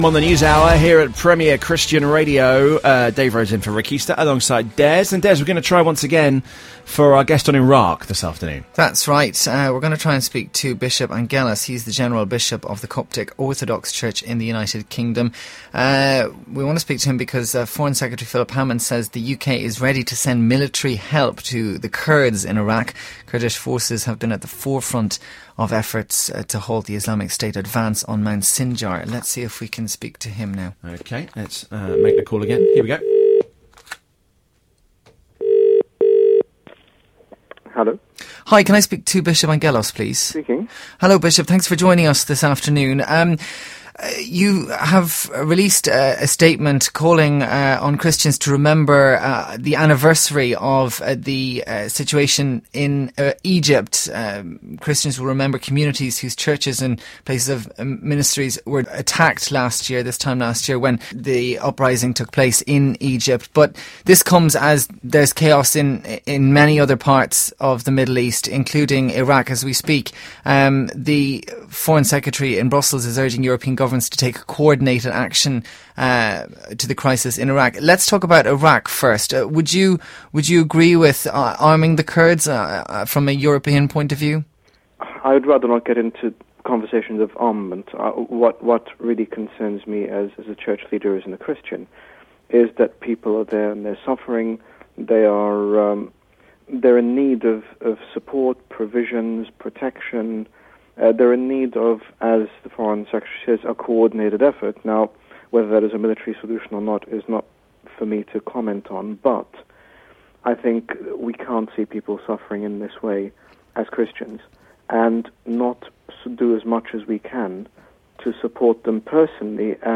On 15 August 2014, Premier Christian Radio interviewed His Grace Bishop Angaelos, General Bishop of the Coptic Orthodox Church in the United Kingdom, regarding the situation in Iraq.
Premier Christian Radio interviews Bishop Angaelos.mp3